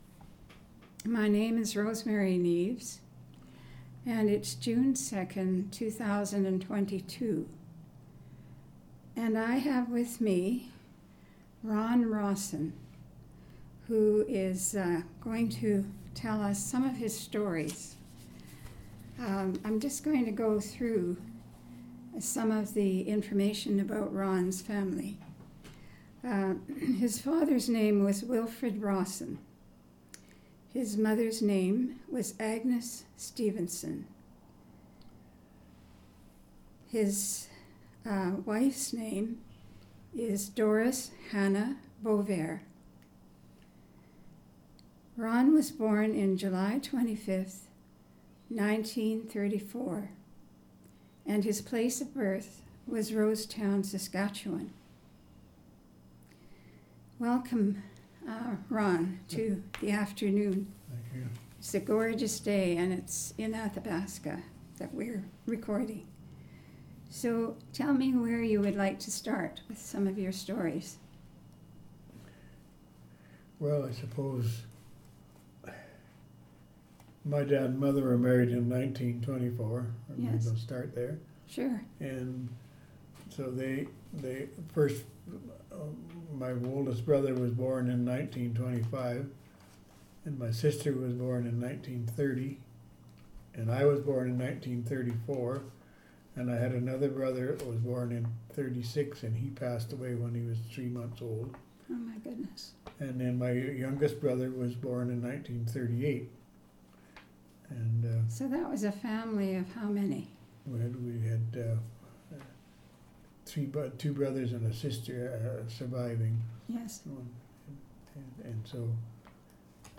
Audio interview, transcript of audio interview and obituary,